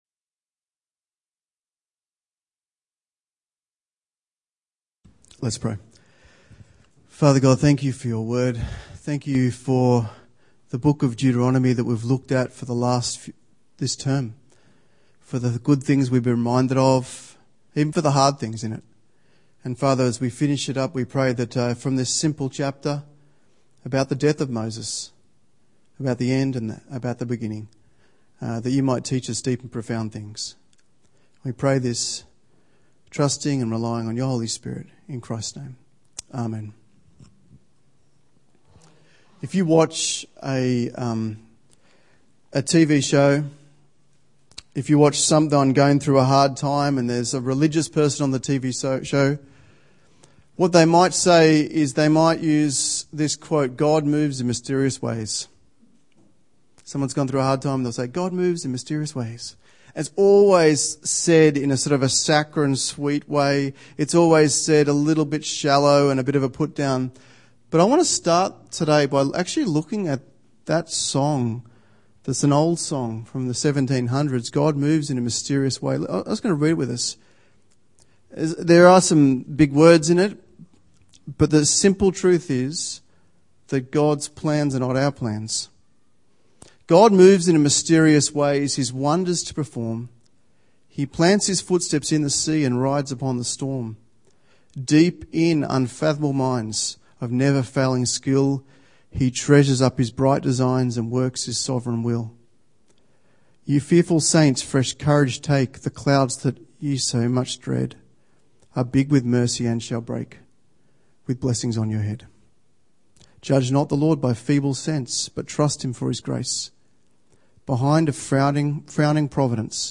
Saturday Church